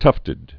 (tŭftĭd)